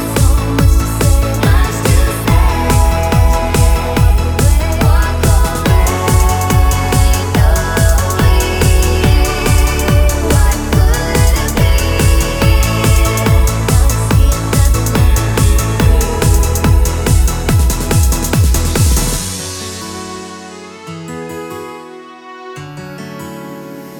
no Backing Vocals Dance 3:39 Buy £1.50